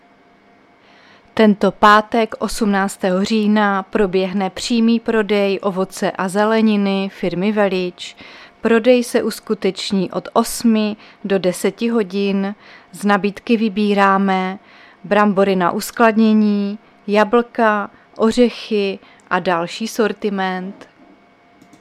Záznam hlášení místního rozhlasu 16.10.2024
Zařazení: Rozhlas